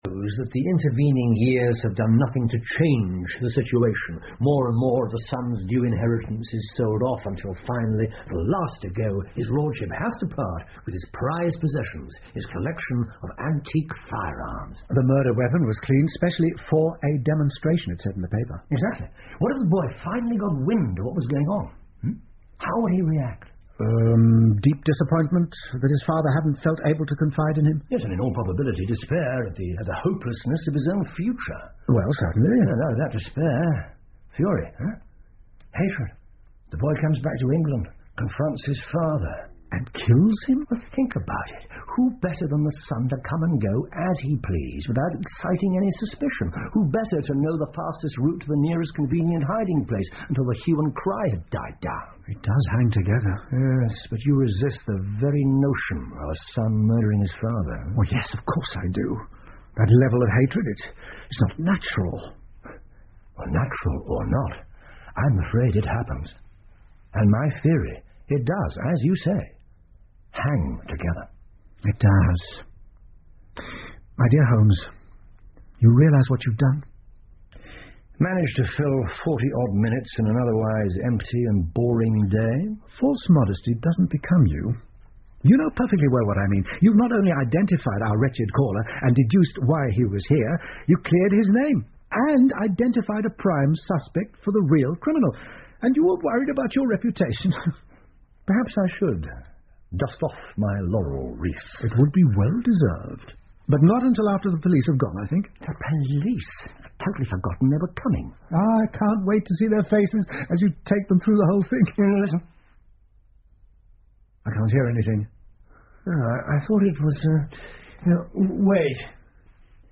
福尔摩斯广播剧 The Abergavenny Murder 9 听力文件下载—在线英语听力室